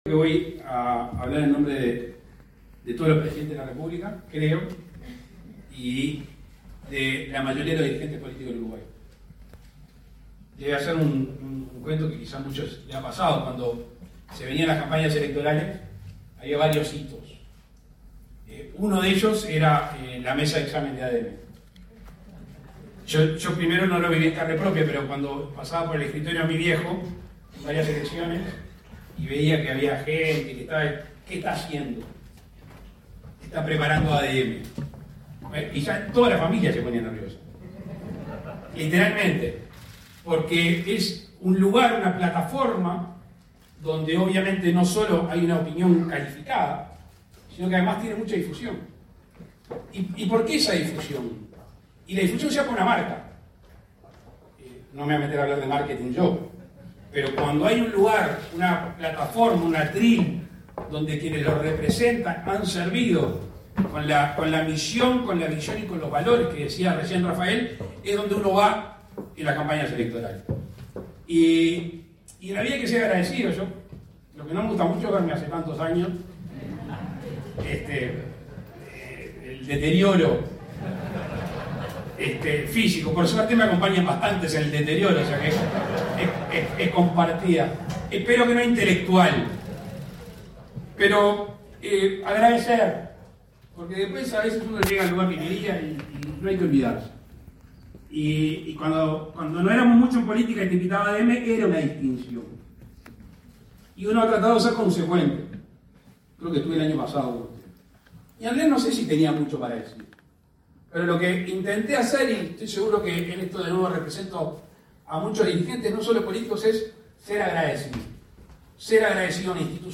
Palabras del presidente de la República, Luis Lacalle Pou, en ADM
El presidente de la República, Luis Lacalle Pou, participó, este 8 de agosto, en el 80.° aniversario de la Asociación de Dirigentes de Marketing (ADM)